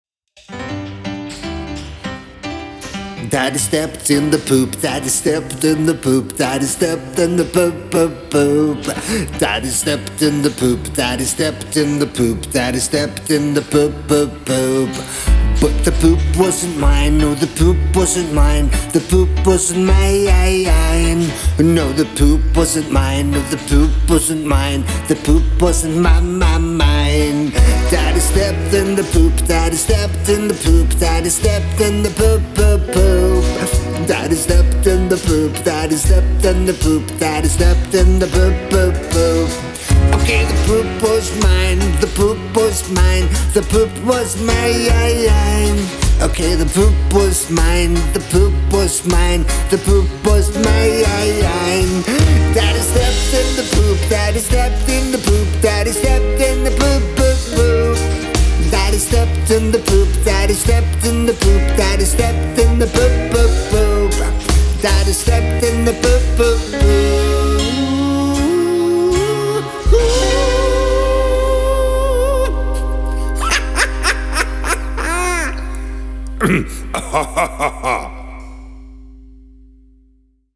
Torsdag 16. desember 2021:  DADDY STEPPED IN THE POOP – BARNESANG (Sang nr 106 – på 106 dager)
Bass
Trommer
Gitar
Keyboards
Skal du spille inn det der i studio?, sa kona.